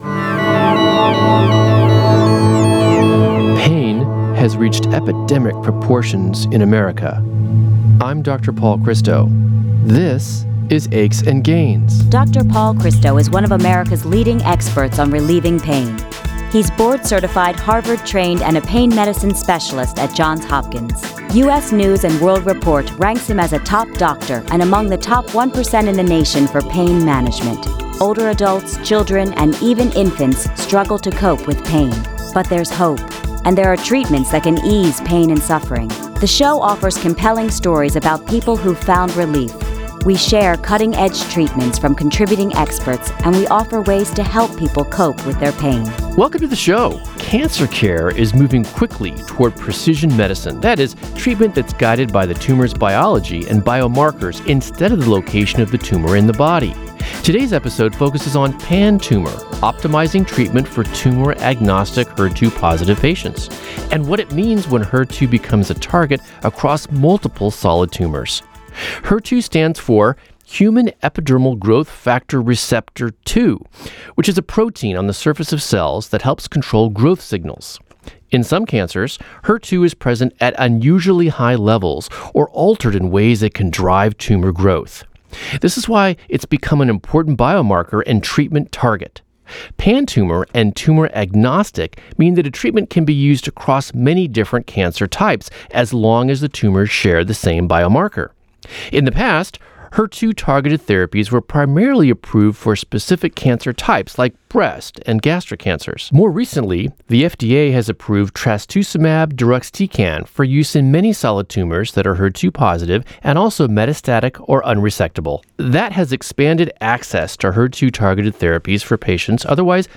America's first radio talk show dedicated to overcoming chronic pain. Hear exclusive interviews and personal stories from celebrity guests.